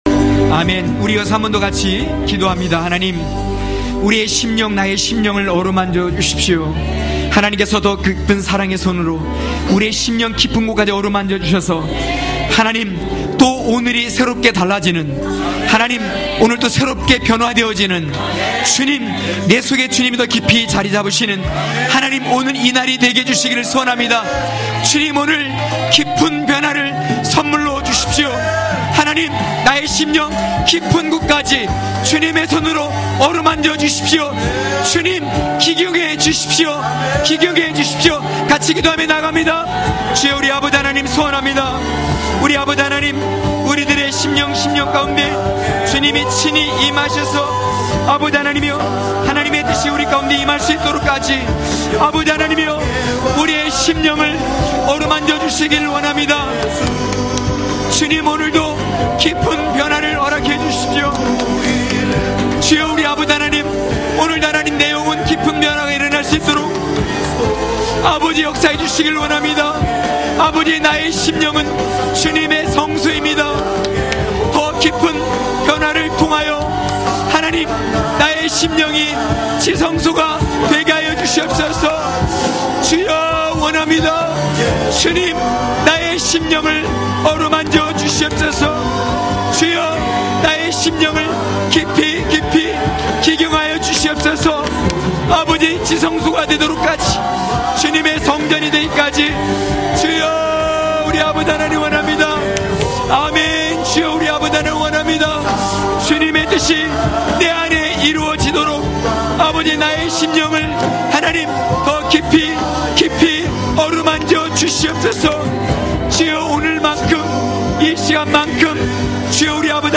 강해설교 - 15.진정한 초막절을 사모하며..(느8장13~18절).mp3